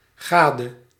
Ääntäminen
US
IPA : /spaʊs/